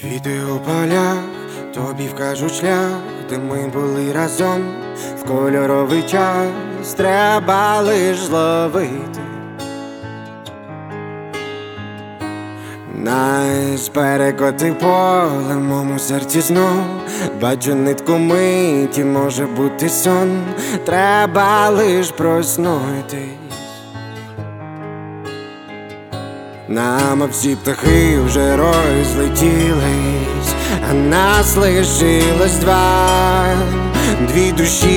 Жанр: Поп / Украинский рок / Украинские